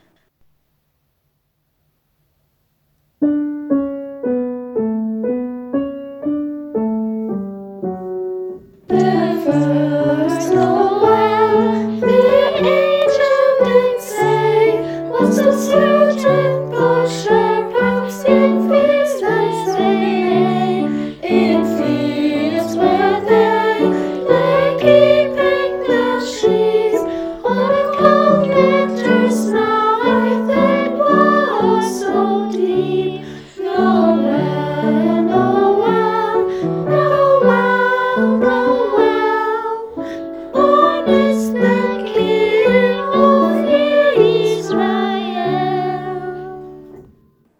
Genießt unsere musikalischen Weihnachtsgrüße – alle einzeln von unseren Chormitgliedern für euch eingesungen und anschließend zum Chorerlebnis zusammengeschnitten.